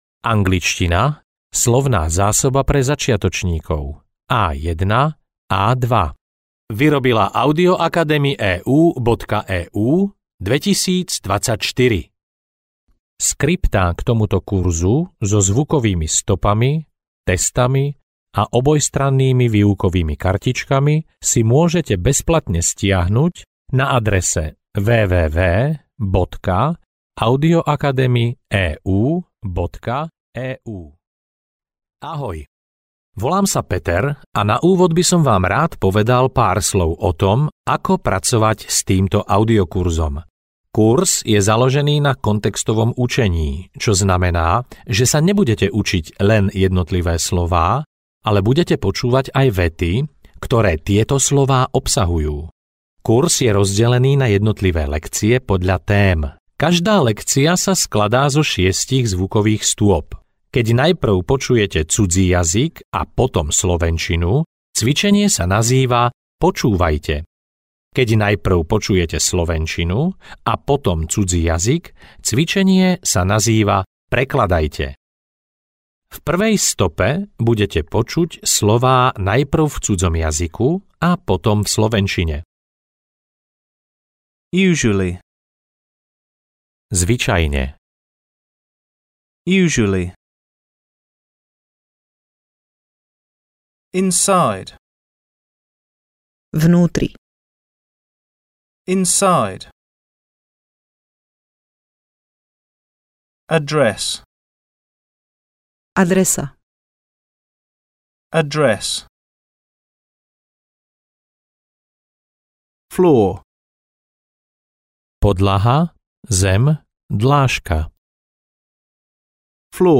Angličtina pre začiatočníkov A1-A2 audiokniha
Ukázka z knihy
anglictina-pre-zaciatocnikov-a1-a2-audiokniha